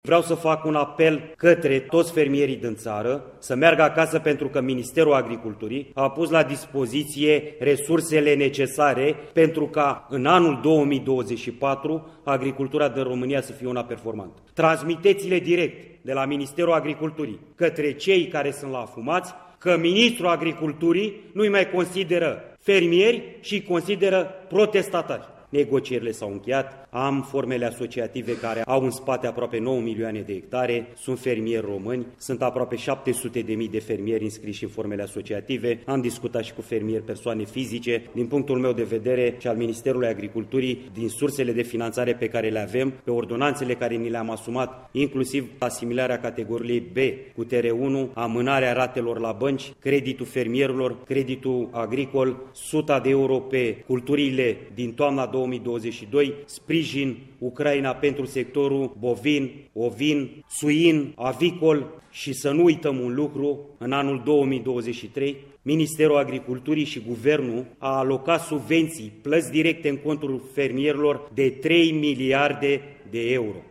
El a avut un mesaj tranşant pentru cei care continuă protestele: